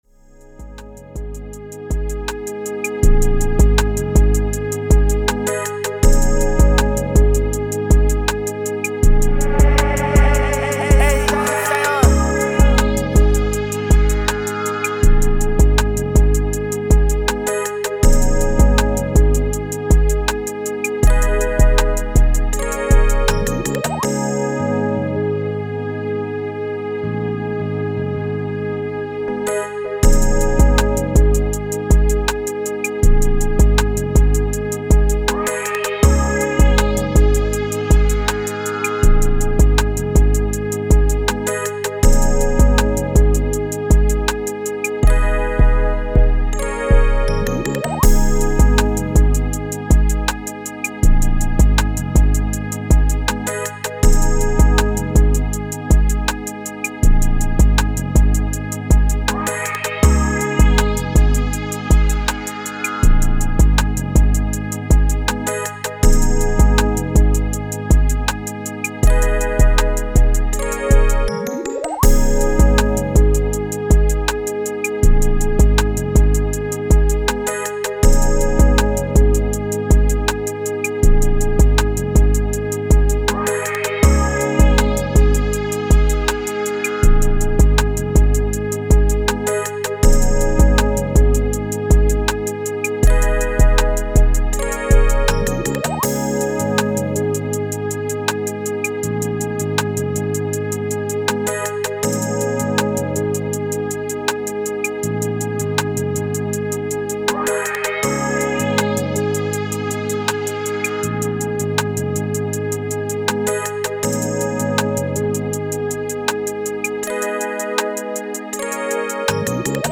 160 G# Minor